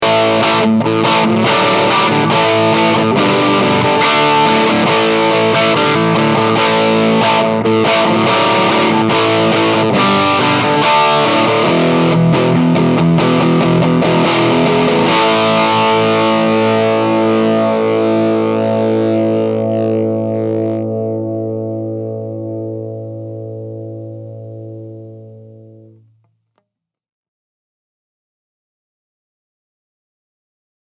The Burstbucker 3 produces a rich tone that’s full of harmonics and overtones, without being overly bright.
Rock Rhythm
I used a Sennheiser e609 instrument mic, and recorded directly into GarageBand with no volume leveling.
treb_rock.mp3